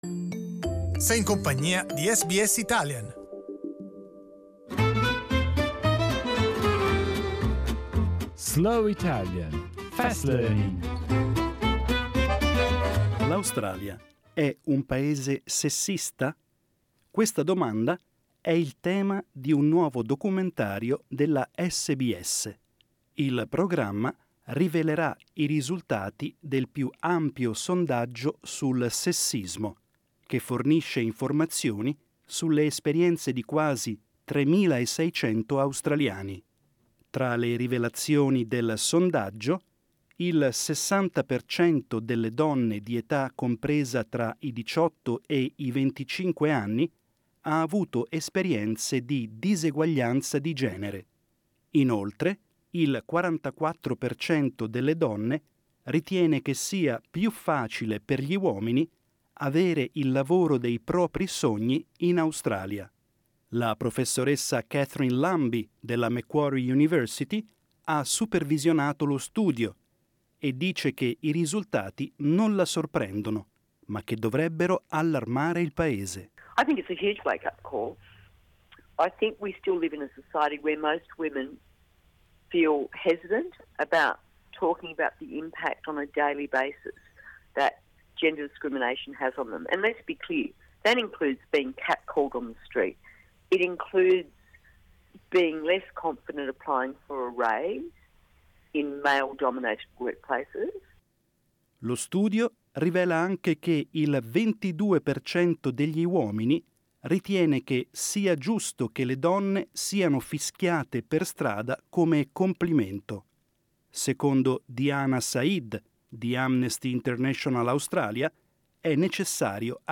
SBS Italian news, with a slower pace. This is Slow Italian, Fast Learning, the very best of the week’s news, read at a slower pace , with Italian and English text available .